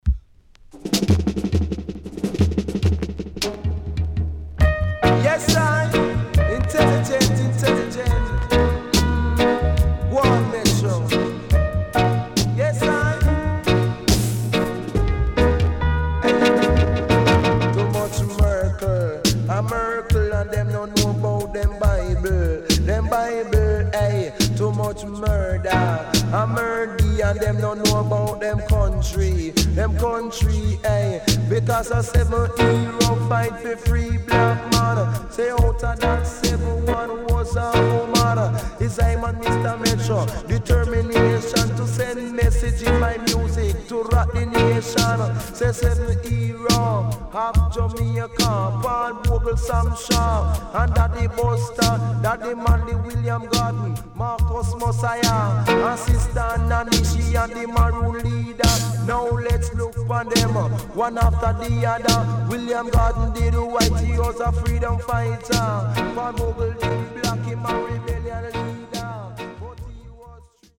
HOME > Back Order [DANCEHALL LP]